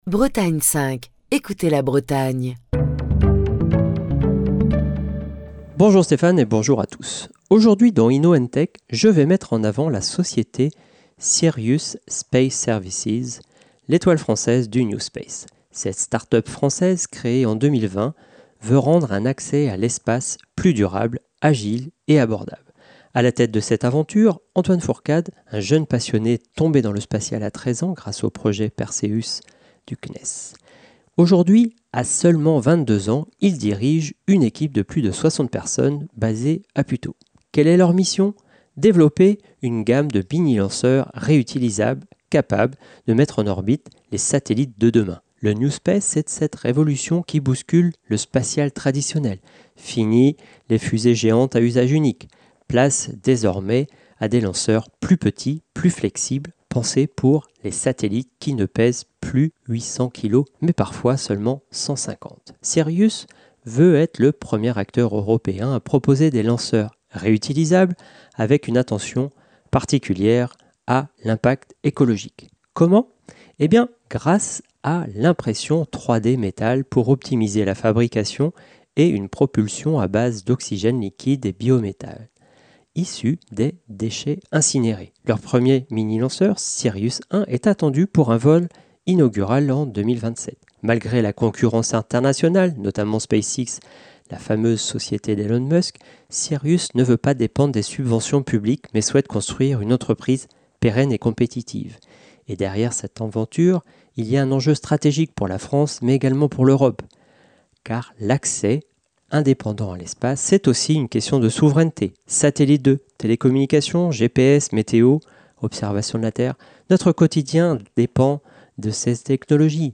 Chronique du 11 décembre 2025.